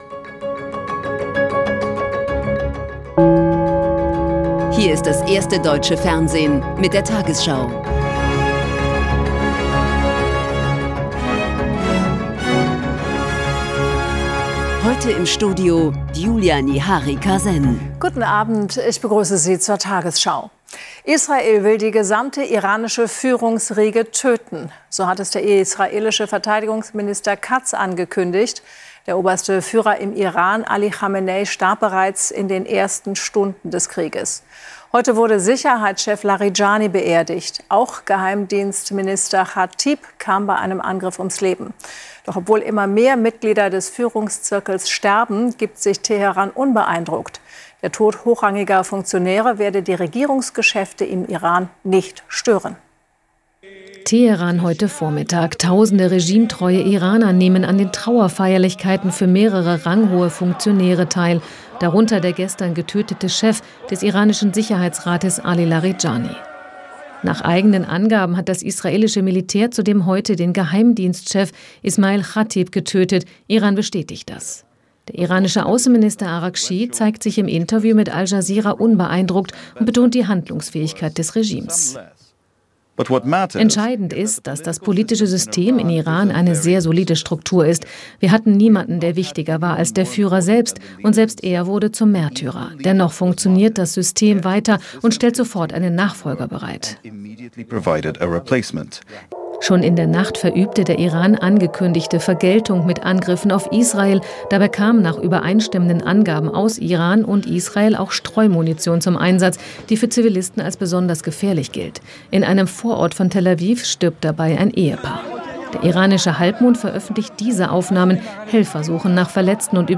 tagesschau 20:00 Uhr, 18.03.2026 ~ tagesschau: Die 20 Uhr Nachrichten (Audio) Podcast